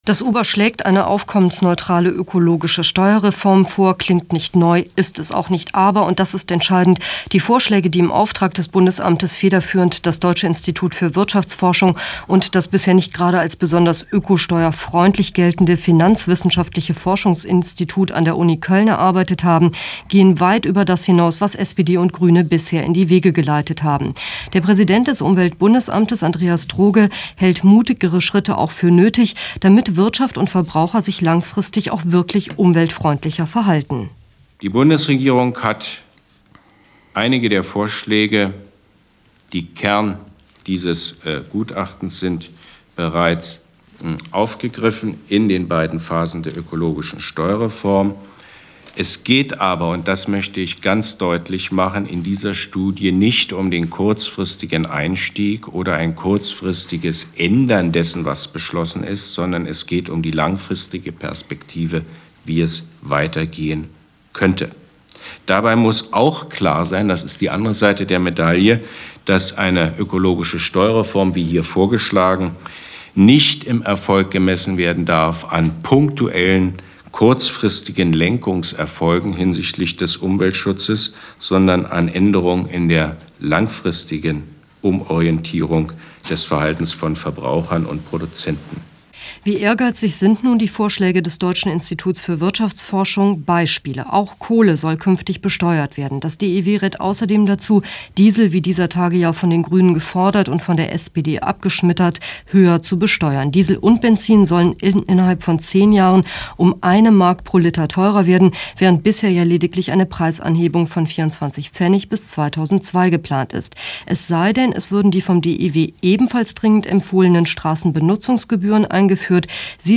Radiobeitrag zu UBA-Vorschlägen für eine ökologische Steuerreform (Deutschlandfunk 1999, gekürzt)